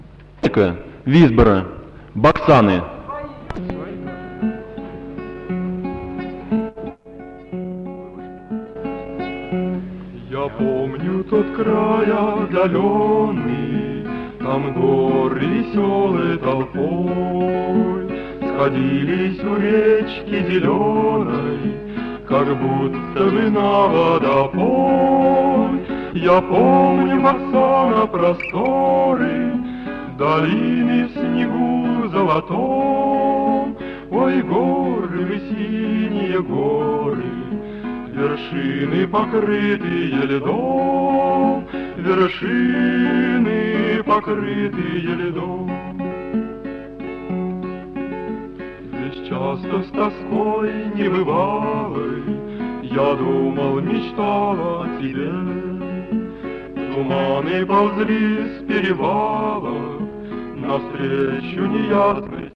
Аудиозаписи Третьего Московского конкурса студенческой песни
ДК МЭИ. 7 декабря 1961 года.
Мужской ансамбль МИТХТ под гитару